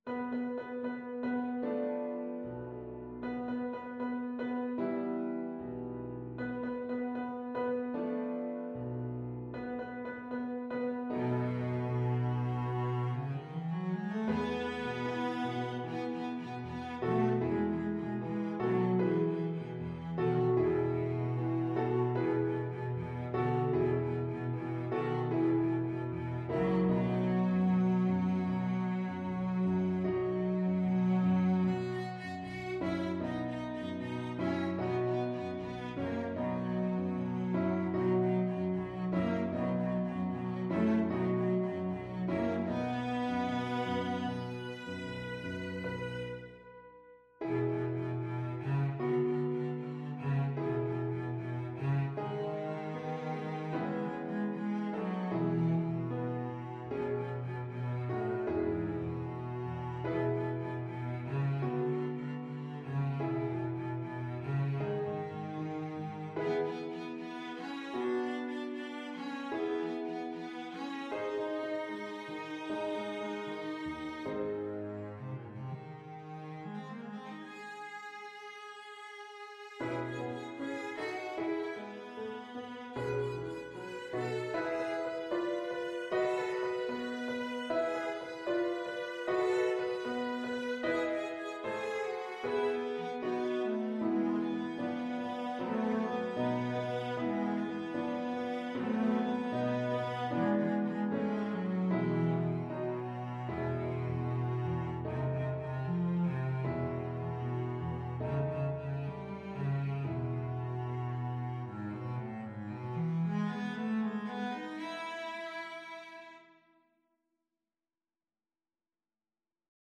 Classical Saint-Saëns, Camille Havanaise, Op.83 Cello version
Cello
2/4 (View more 2/4 Music)
=76 Allegretto lusinghiero =104
E major (Sounding Pitch) (View more E major Music for Cello )
Classical (View more Classical Cello Music)